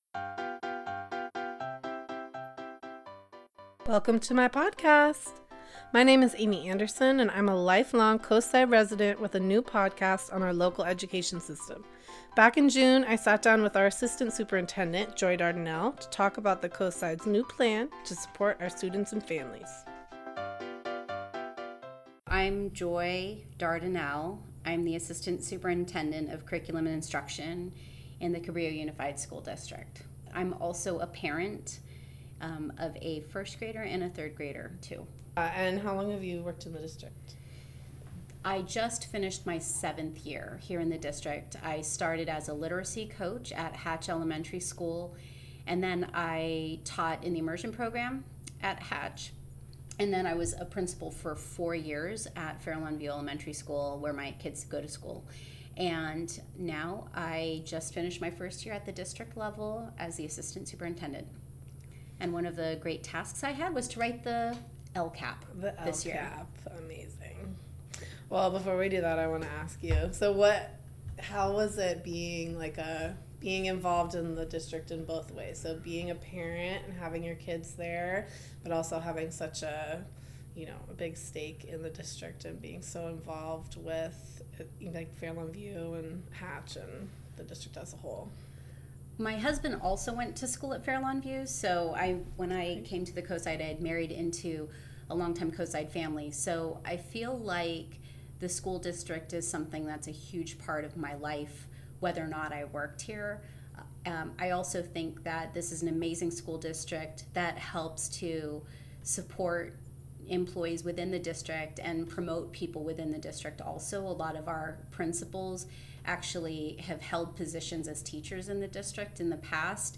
This interview was recorded June 2017. https